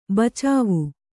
♪ bacāvu